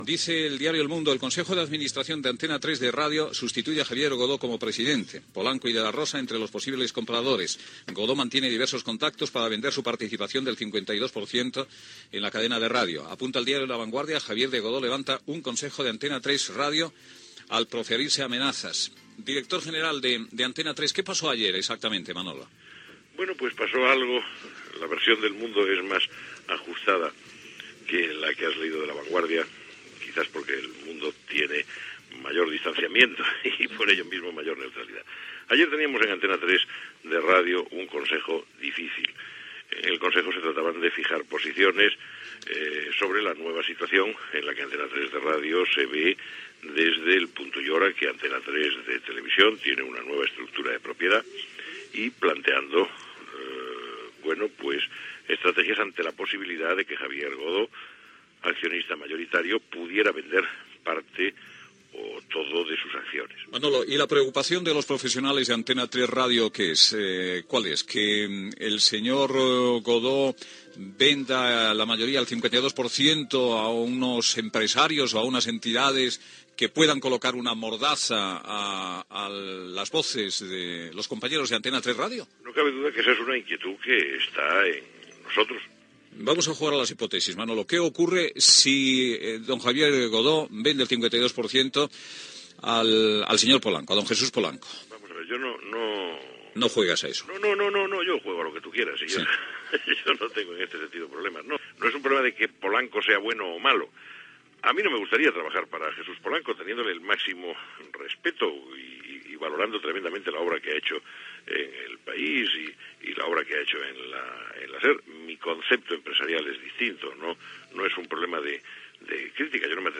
Fragment d'una entrevista al periodista Manolo Martín Ferrand, fins aleshores Director General d'Antena 3 Radio , sobre un consell d'administració complicat
Info-entreteniment